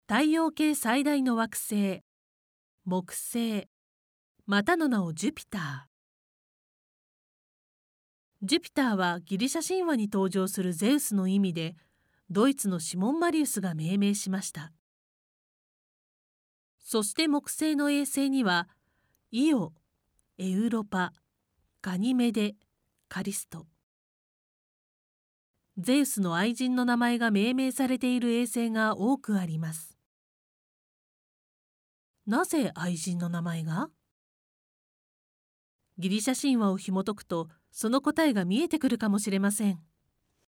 ナレーション4 お使いのブラウザは audio タグをサポートしていません。